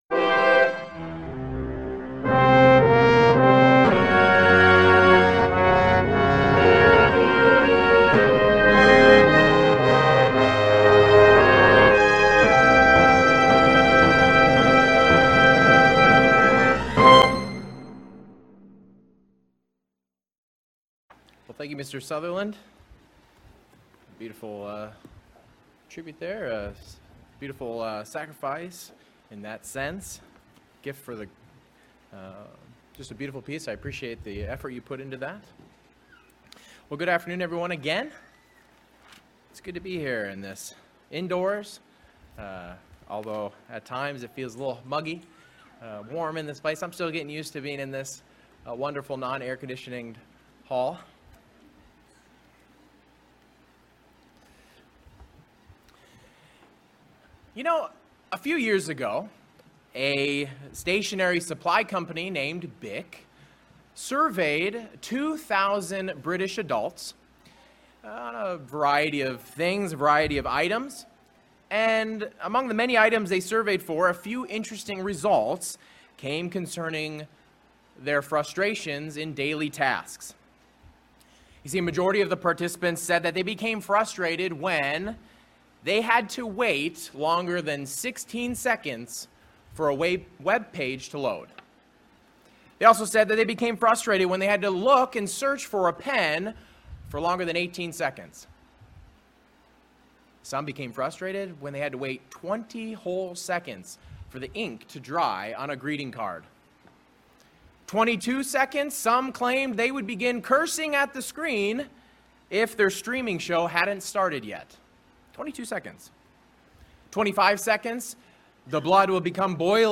What does scripture say about patience? This message discusses the example God set for us in being patient.